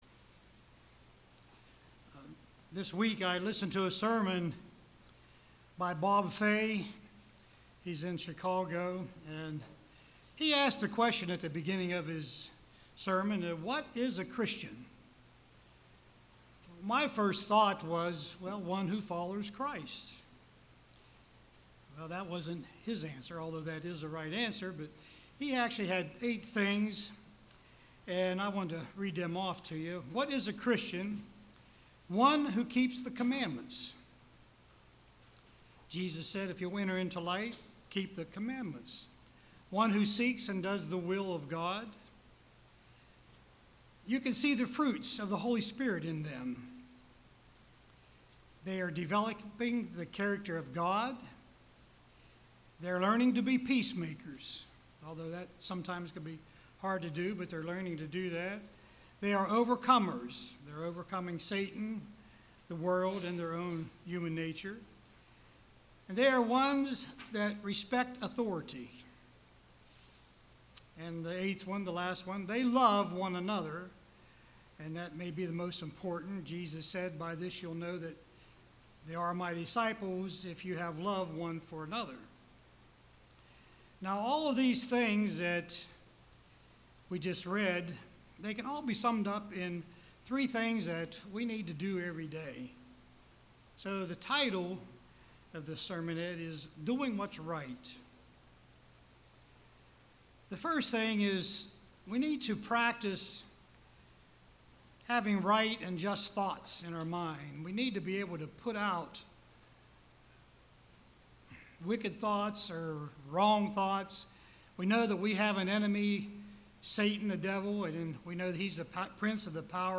Print Doing What is Right UCG Sermon